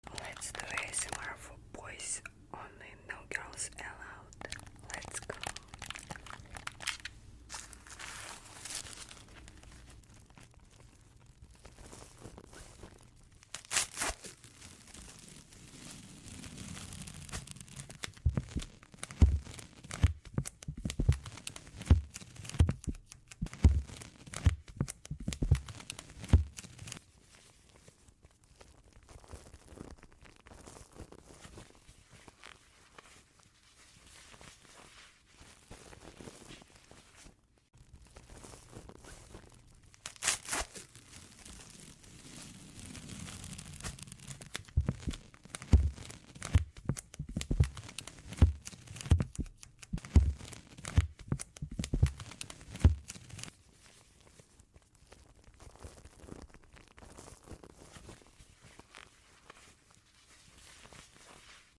THE MOST SATISFYING ASMR FOR Sound Effects Free Download